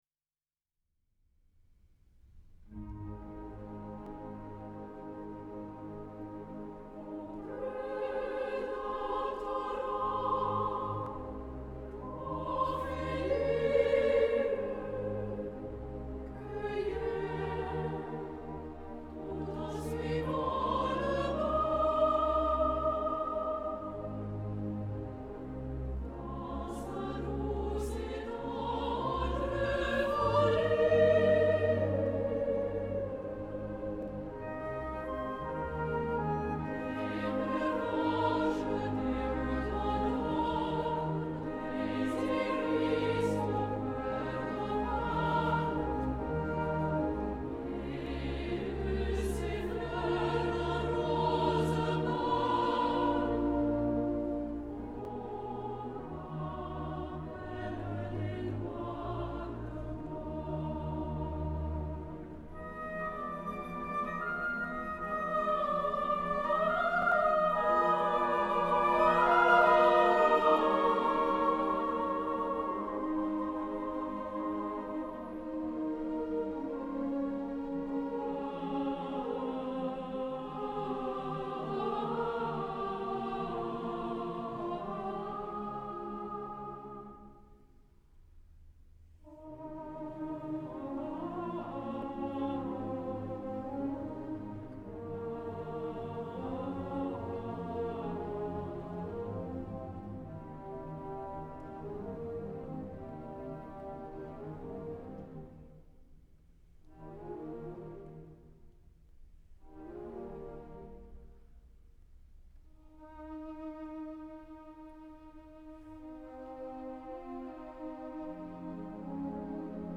La Mort d’Ophélie for solo voice and piano, originally composed ten years earlier, appeared modified for two-part women’s chorus and chamber orchestra in 1848, the version that would finally be published as the second piece of Tristia.
Like Le Ballet des ombres, each of the stanzas ends with similar musical material. In addition, at the end of the first and last stanza the chorus sings a melismatic “Ah!,” expressing the underlying mood of lamentation. Abbreviated musical reminiscences of this motive appear at the end of the second stanza in the violins doubled by flute, and then in violins alone at the end of the third stanza.